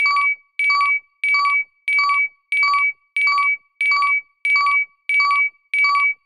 フリー音源効果音「警告音」「緊急音」です。
フリー音源 「警告音」「緊急音」4
緊急音4